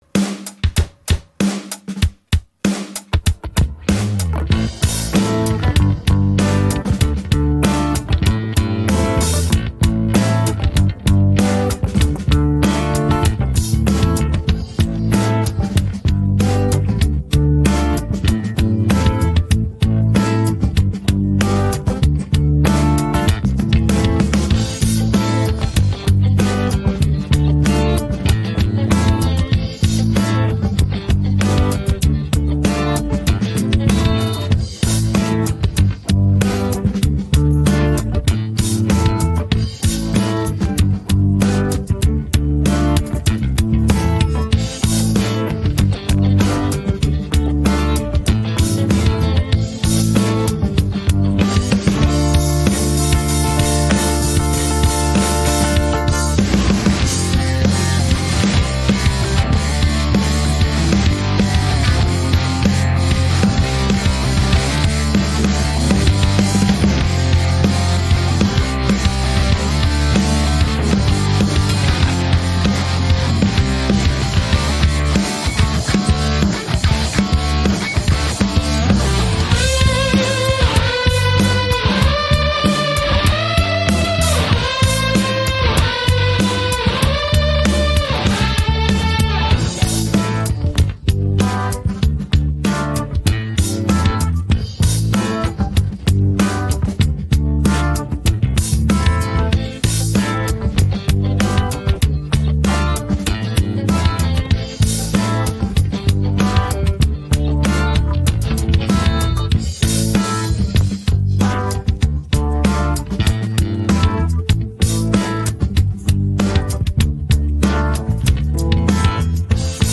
rock караоке